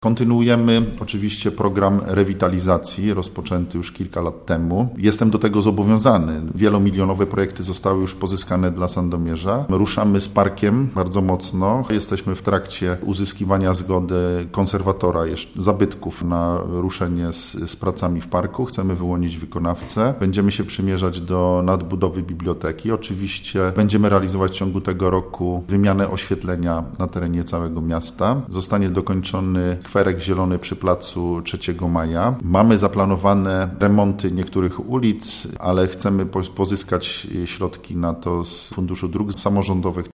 – mówi burmistrz Sandomierza Marcin Marzec.